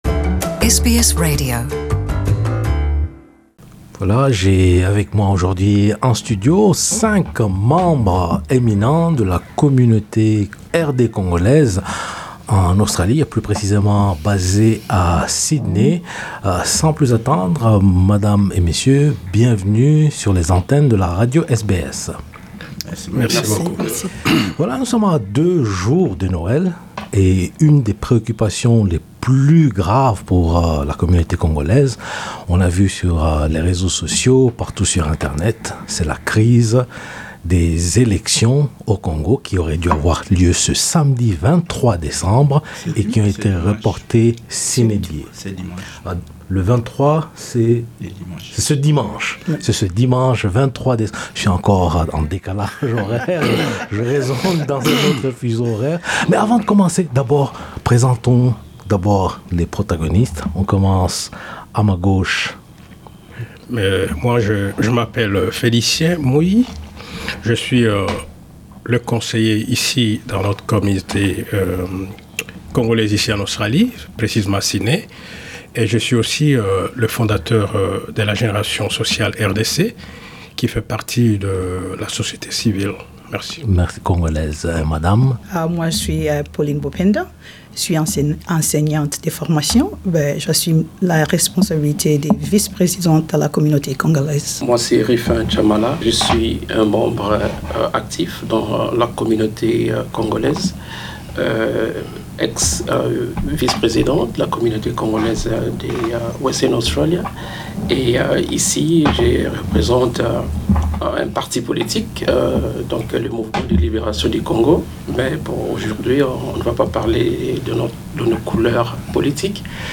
Cinq leaders de la communauté congolaise d’Australie discutent de la crise profonde que traverse la République Démocratique du Congo suite au énième report des élections sur fond de violences et de paralysie sociale.